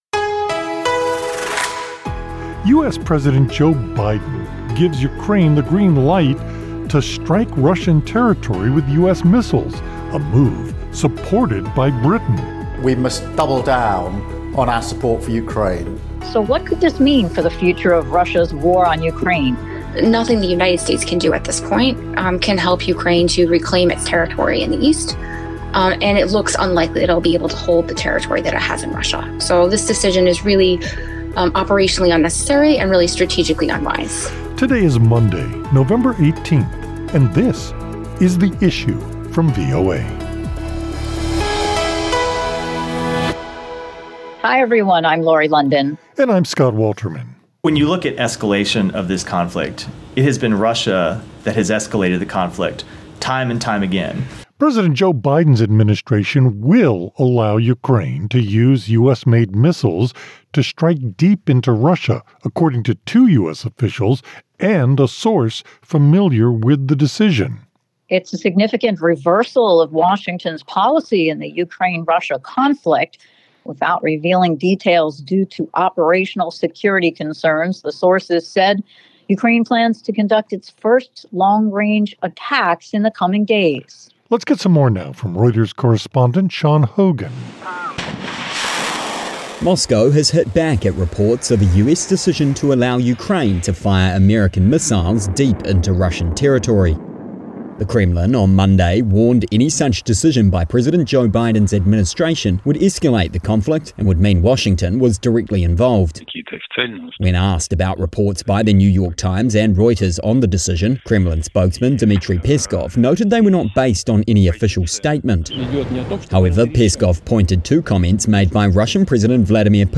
The Kremlin warned Monday that President Joe Biden’s decision to let Ukraine strike targets inside Russia with U.S.-supplied longer-range missiles adds “fuel to the fire” of the war and would escalate international tensions even higher. Biden’s shift in policy added an uncertain, new factor to the conflict on the eve of the 1,000-day milestone since Russia began its full-scale invasion in 2022. A conversation